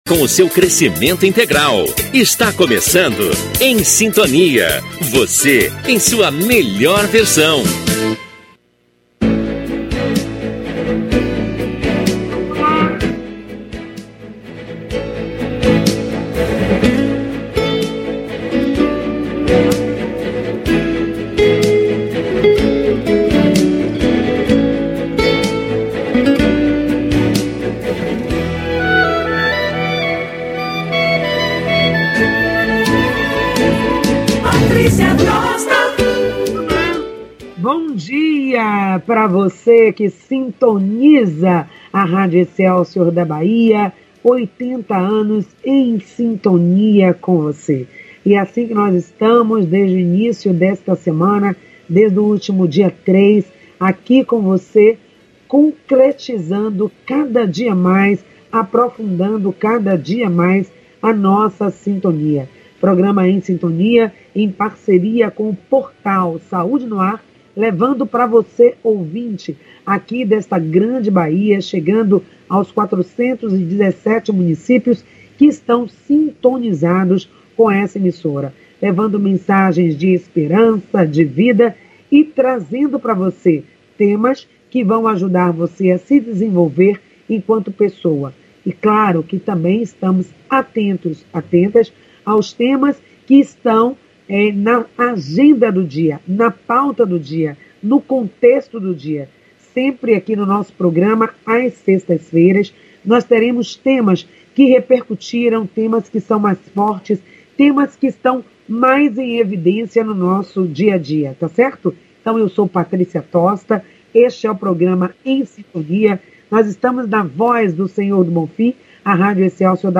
O Programa Em Sintonia foi apresentado aos ouvintes através da Rádio Excelsior AM840 em 03 de janeiro 2022, ano em que a emissora completou 80 anos.
Ao vivo, com conteúdo eclético e focado no desenvolvimento humano, alinhado aos valores cristãos da ética e respeito à vida, o Programa coloca o ouvinte Em Sintonia com sua essência e em processo de desenvolvimento das distintas dimensões humanas: física, mental, espiritual, intelectual, emocional, afetiva/relacional, profissional, financeira e cultural, sem abrir mão dos conteúdos jornalísticos através de informações atuais nos âmbitos: local, nacional e in